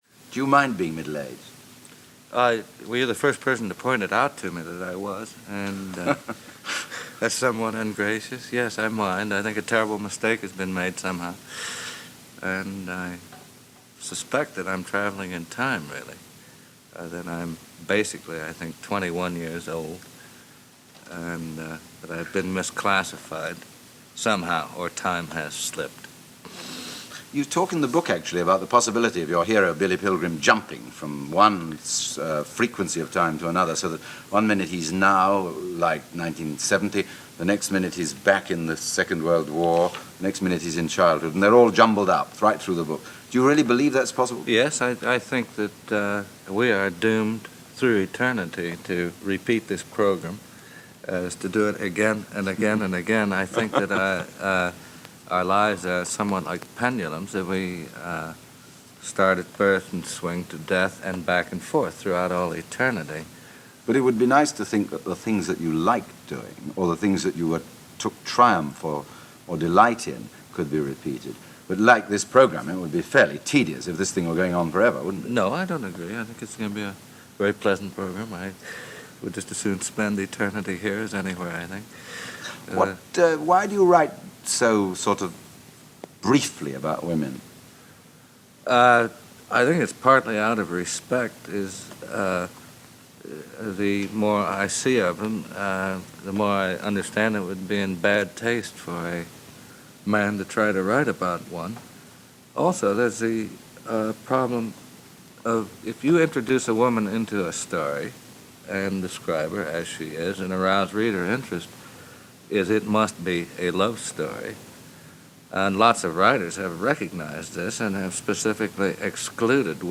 1970： KURT VONNEGUT Interview ｜ Review ｜ Writers and Wordsmiths ｜ BBC Archive [LlALlVXe_p8].opus